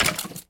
Minecraft.Client / Windows64Media / Sound / Minecraft / mob / skeleton / hurt4.ogg
hurt4.ogg